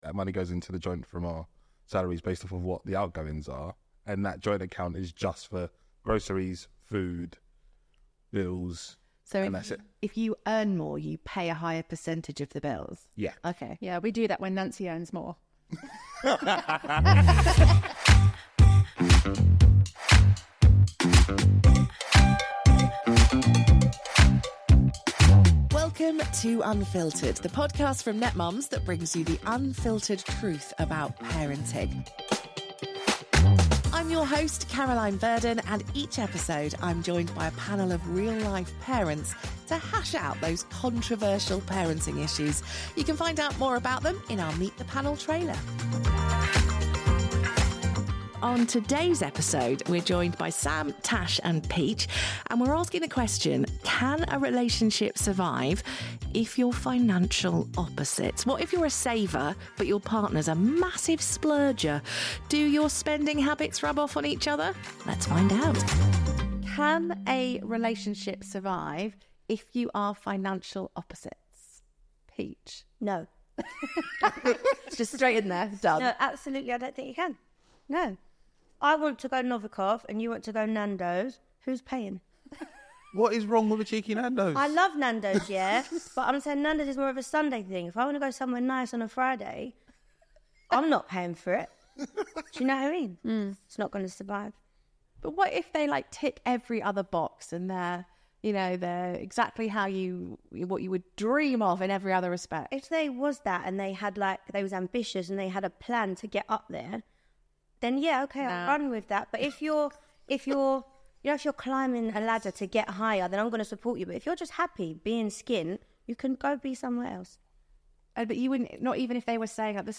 What happens when one half is a spender and the other is a saver? Our parents speak frankly about their family finances and how their attitudes towards money and spending can affect their partnerships… and their parenting.